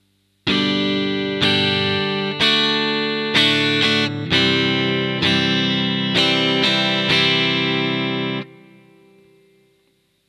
The chords are simple (G, D and C) so that you can focus on the strumming, picking and rhythm approaches.
Exercise no.1 introduces the chords and a basic strumming pattern.